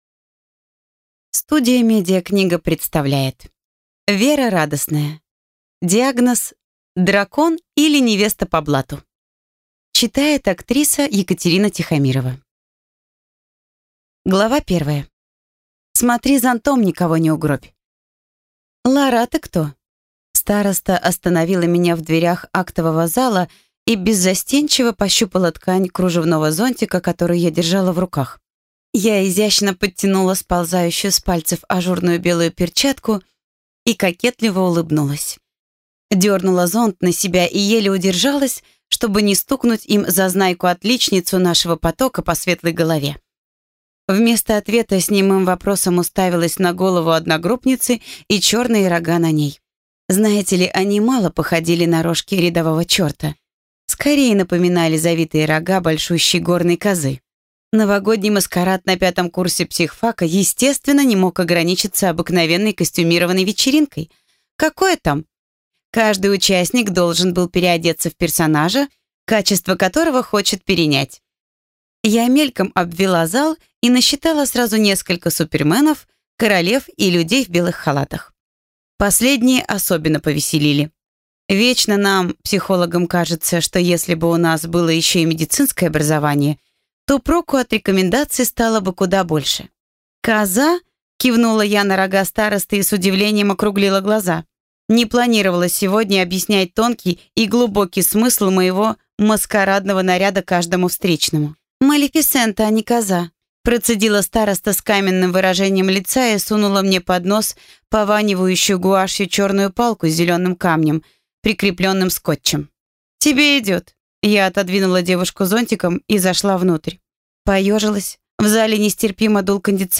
Аудиокнига Диагноз: Дракон, или Невеста по блату | Библиотека аудиокниг